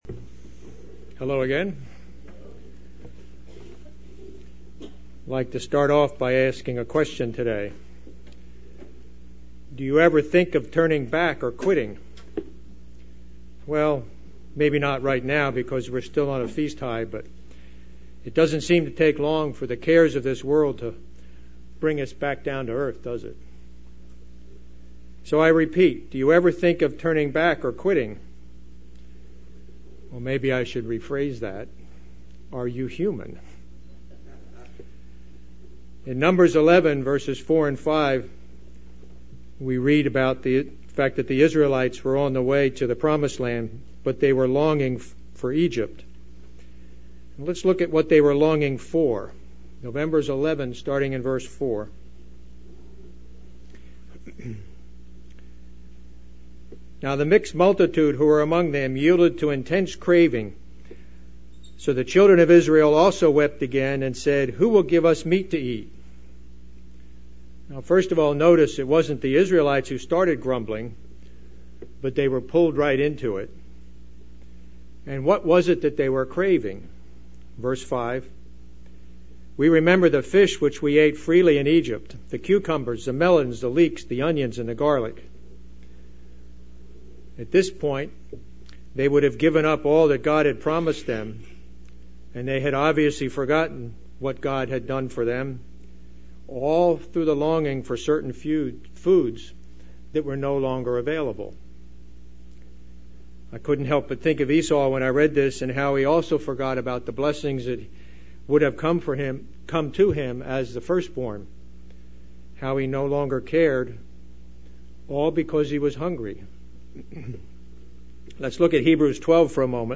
Never let truth become commonplace so that we would be willing to give it up UCG Sermon Studying the bible?